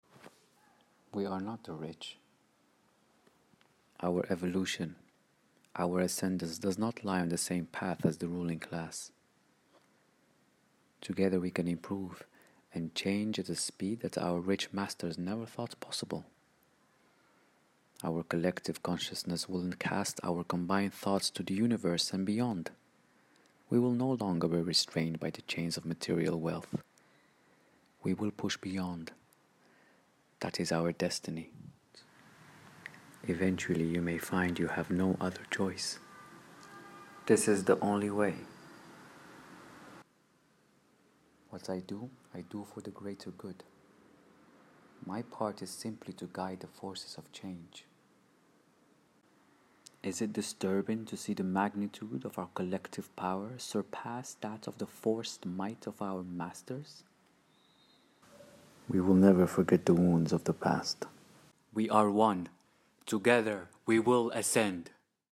Arabic accent